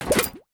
UIClick_Bubble Pop Metallic 02.wav